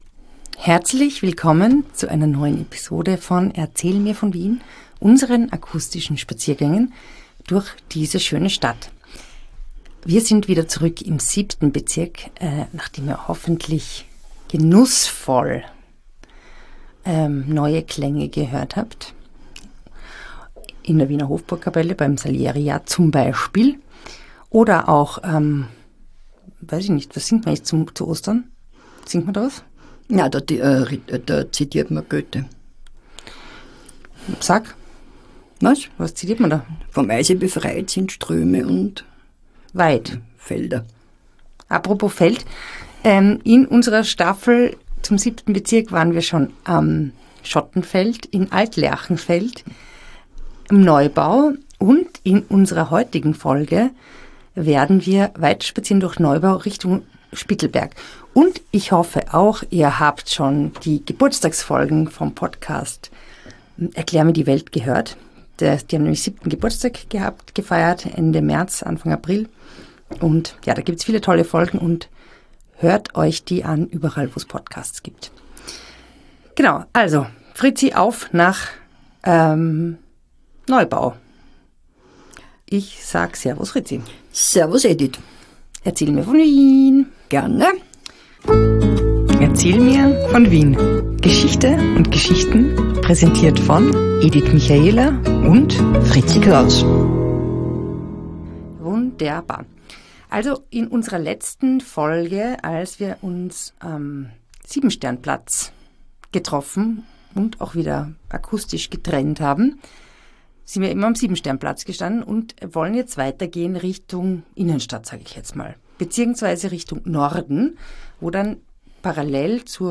Die beiden spazieren durch Wien und unterhalten sich über bekannte und unbekannte Orte, prägende Persönlichkeiten und die vielen kuriosen Geschichten, die es an allen Ecken, in allen Grätzeln und Bezirken Wien zu entdecken gibt.